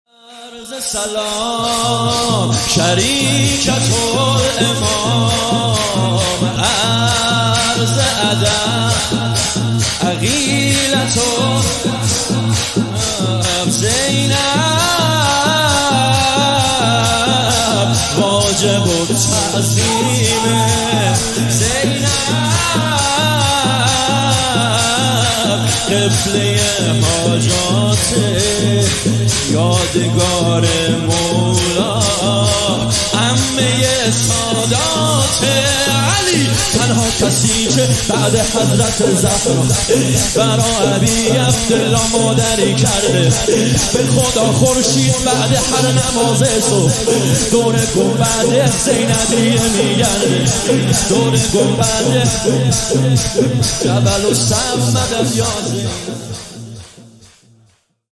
مداحی
شهادت امام صادق(ع) هیئت ام ابیها(س)قم 28 خرداد 1399